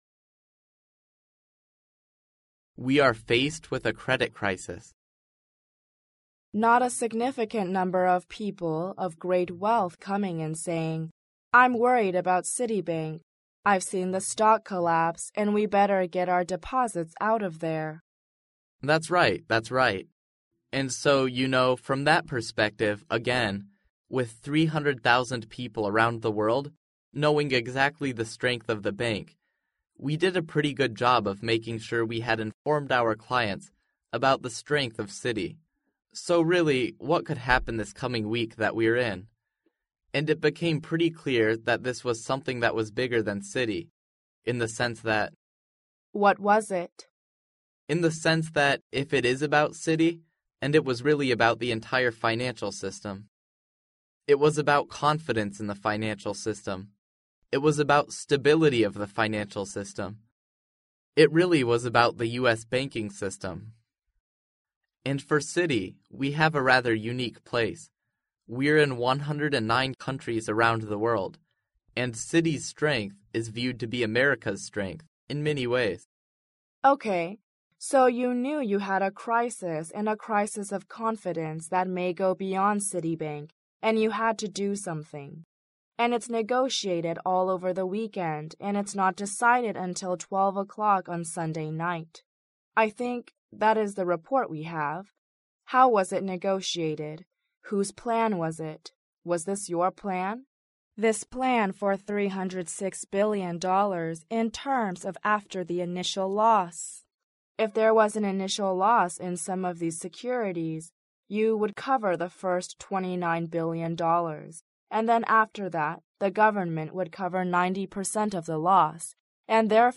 世界500强CEO访谈 第31期:花旗集团潘迪特 面临着信用危机(1) 听力文件下载—在线英语听力室